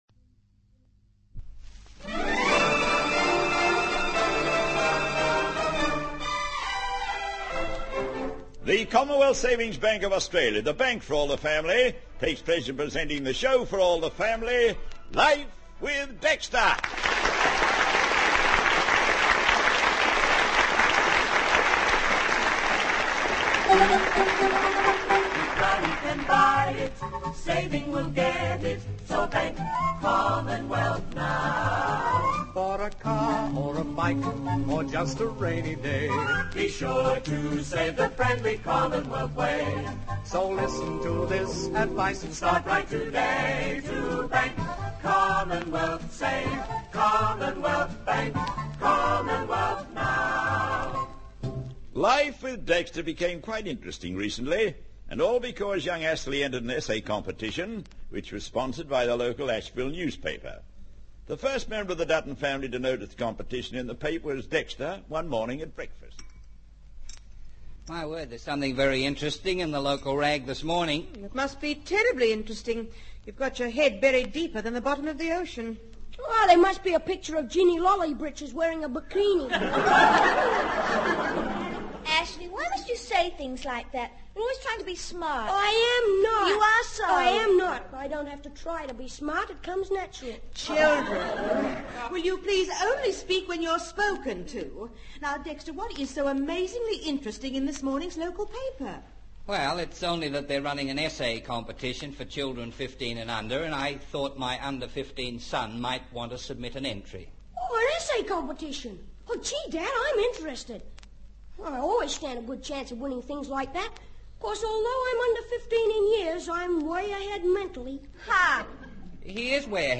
"Life with Dexter" was a popular Australian radio comedy program that aired from the 1950s through to the mid-1960s.